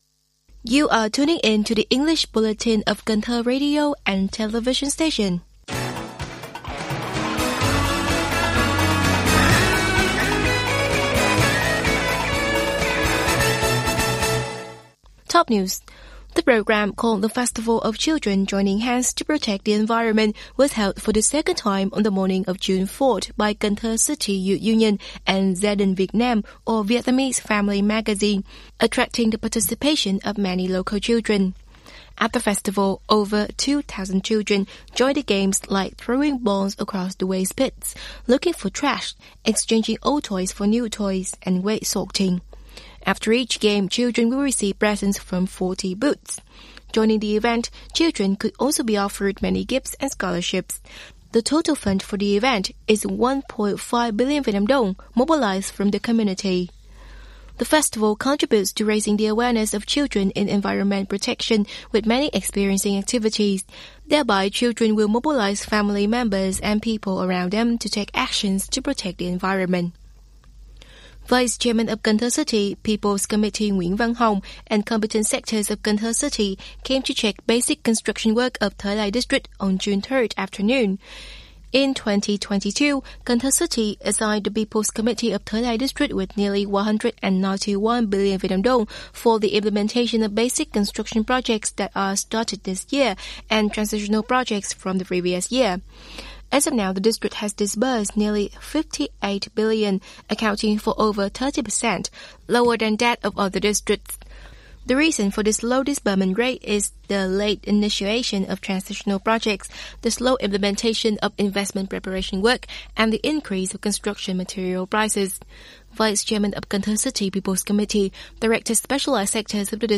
Bản tin tiếng Anh 4/6/2022
Kính mời quý thính giả nghe Bản tin tiếng Anh của Đài Phát thanh và Truyền hình thành phố Cần Thơ